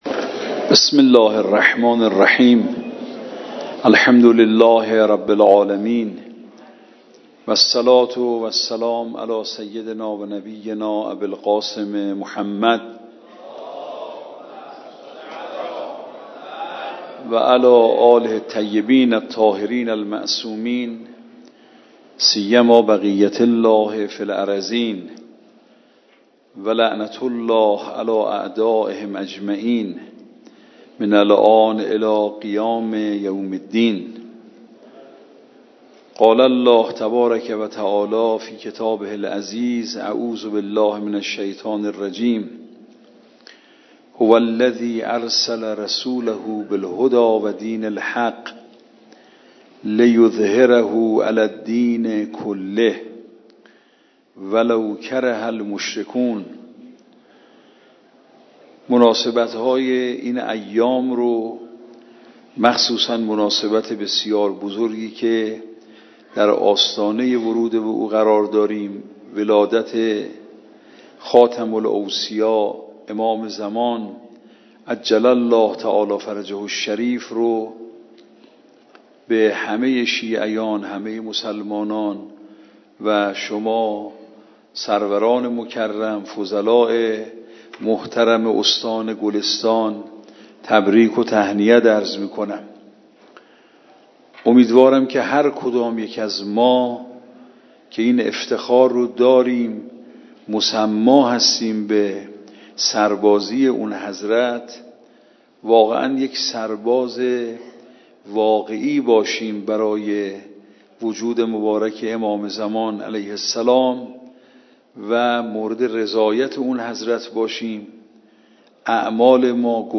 صوت | سخنان آیت‌الله فاضل لنکرانی در دیدار با اساتید و طلاب استان گلستان
به گزارش خبرگزاری بین‌المللی اهل‌بیت(ع) ـ ابنا ـ جمعی از اساتید و علما و طلاب استان گلستان با حضور در مرکز فقهی ائمه اطهار(ع) در شهر قم با آیت الله محمدجواد فاضل لنکرانی رئیس این مرکز، دیدار کردند.